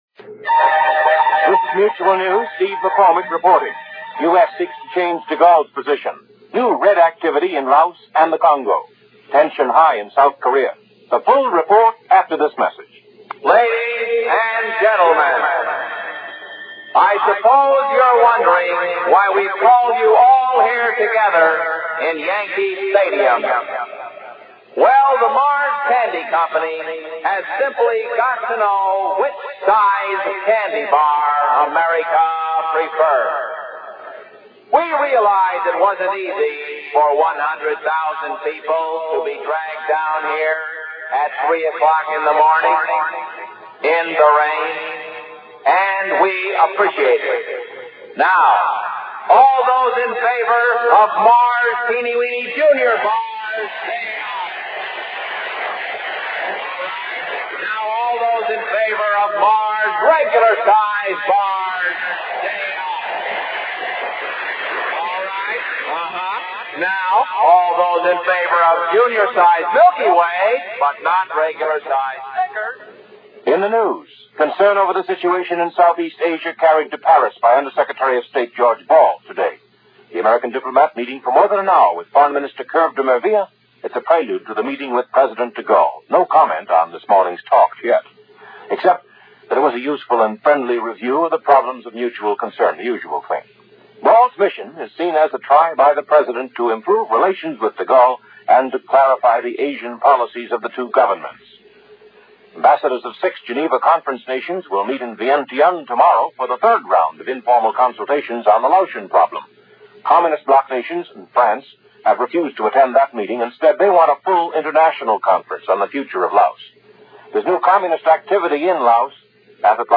And along with Laos that’s just a little of what happened, this June 5, 1964 as presented by Mutual News On The Hour.